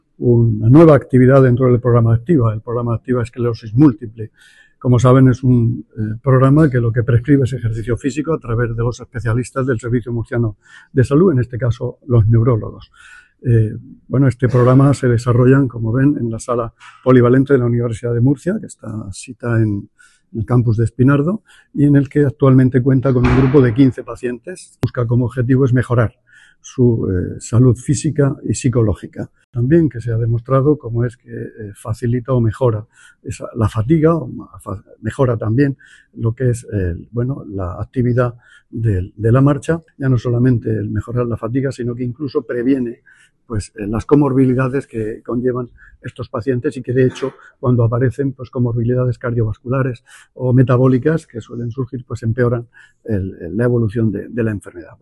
Sonido/ Declaraciones del consejero de Salud sobre el nuevo programa Activa para pacientes con esclerosis múltiple